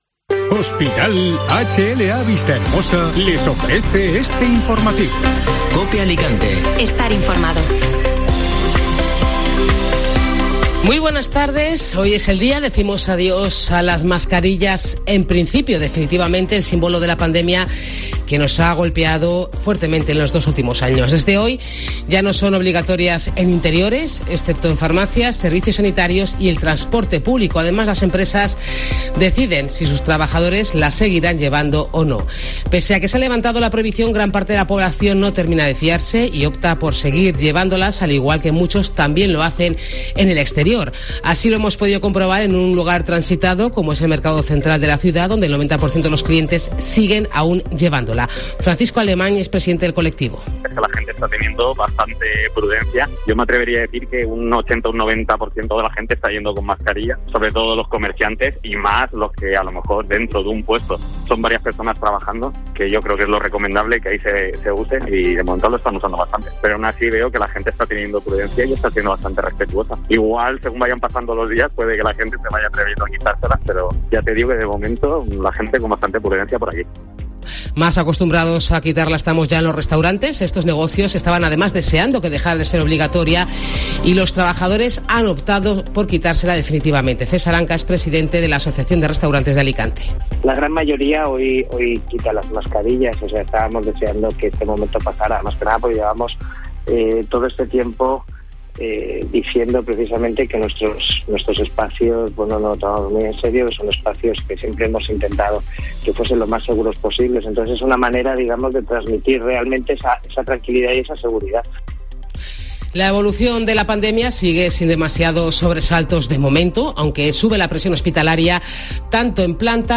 Informativo Mediodía COPE (Miércoles 20 de abril)